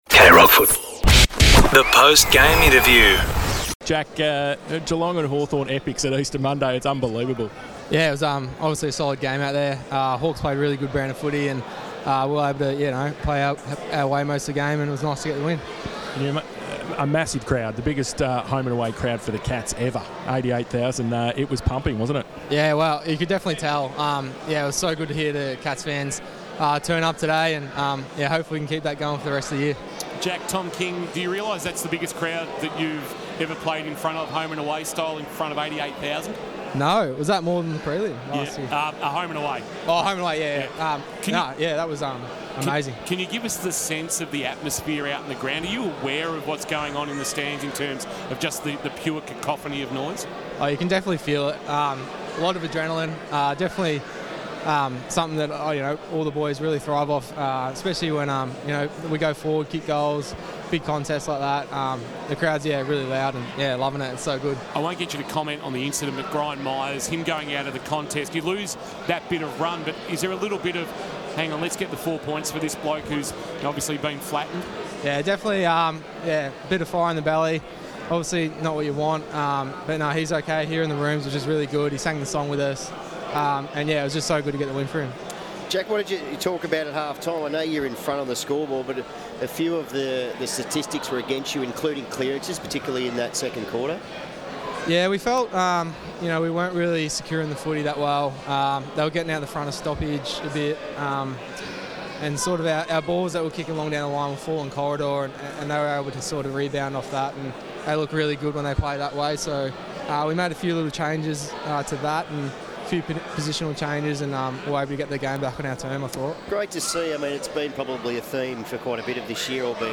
2025 - AFL - Round 6 - Geelong vs. Hawthorn: Post-match interview - Jack Bowes (Geelong Cats)